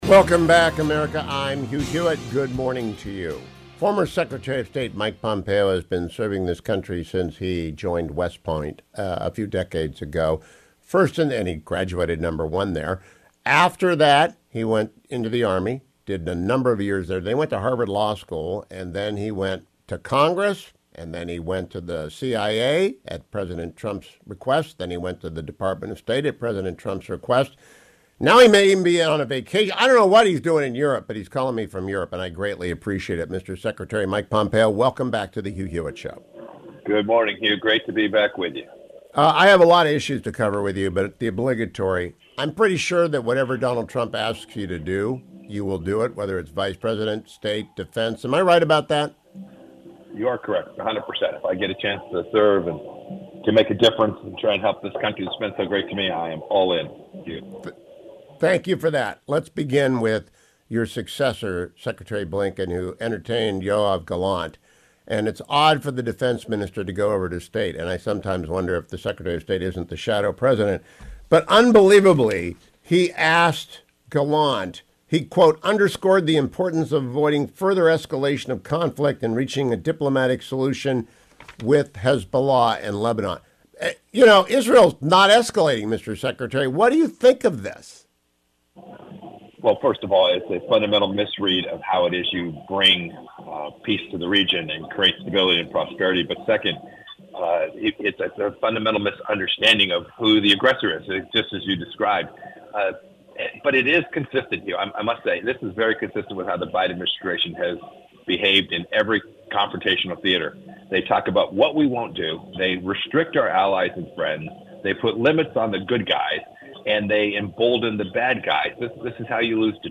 Former Secretary of State Mike Pompeo joined me this AM: